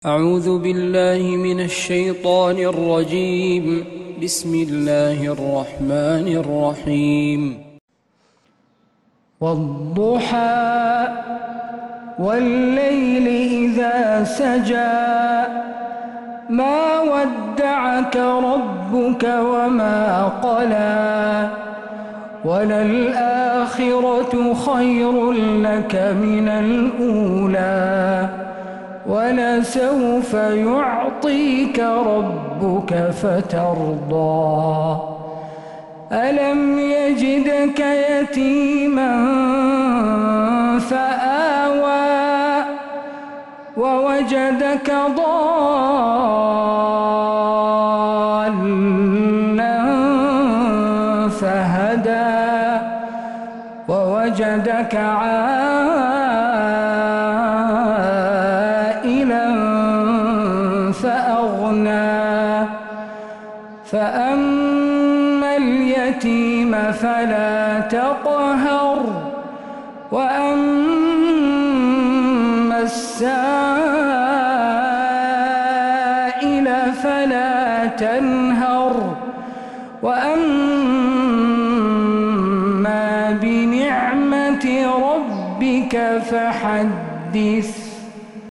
من مغربيات الحرم النبوي